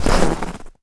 High Quality Footsteps
STEPS Snow, Run 16.wav